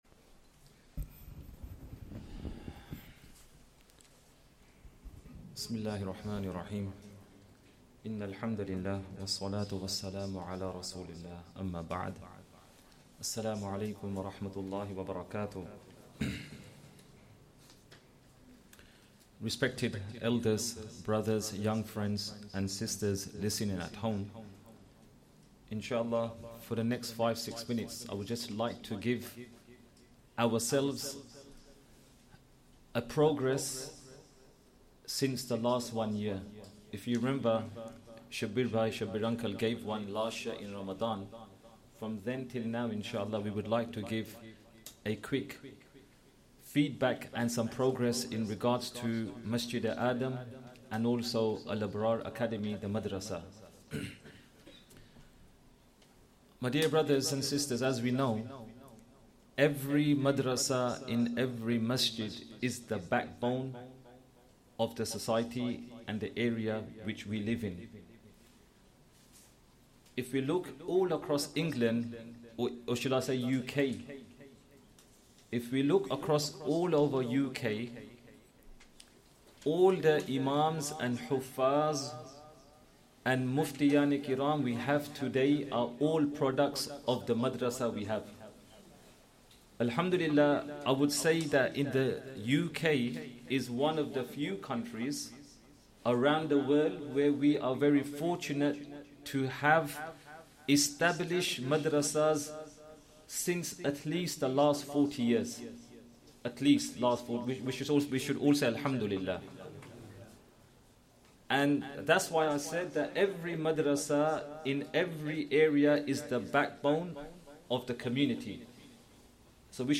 Esha + 1st Tarawih prayer - 7th Ramadan 2024